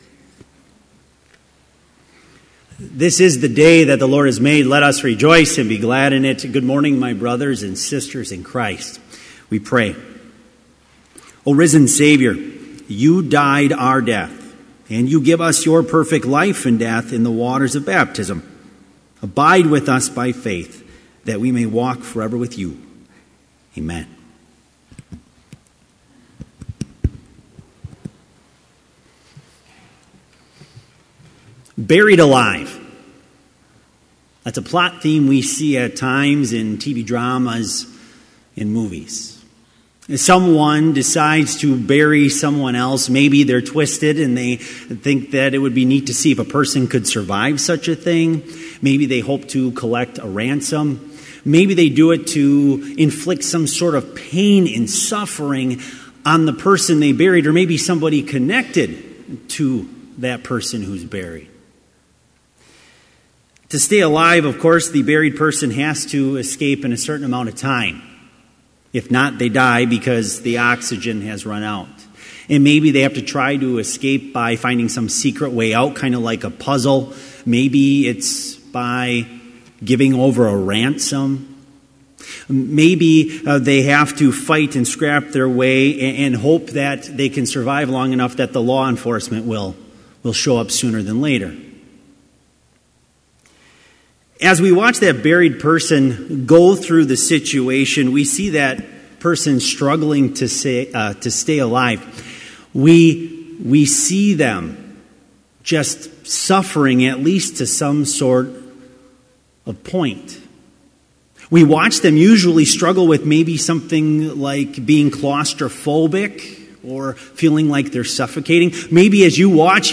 Complete service audio for Chapel - February 16, 2018